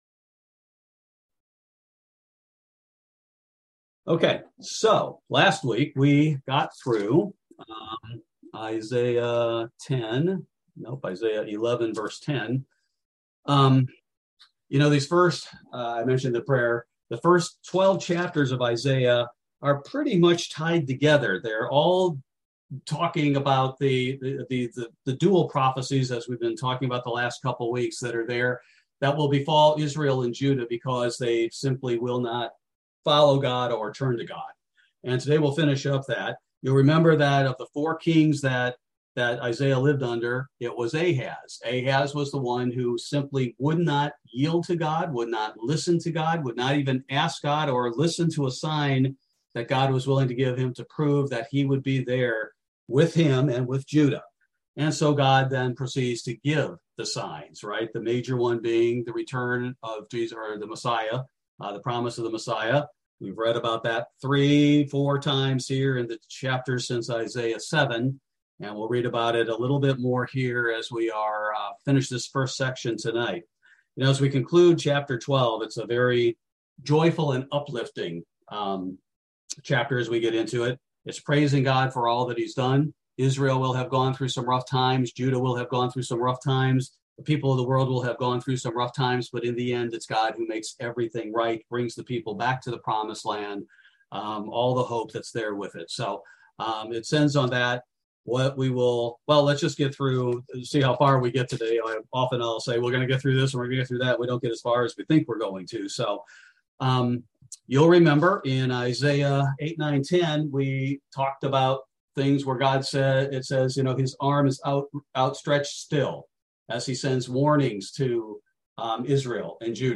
Bible Study: September 14, 2022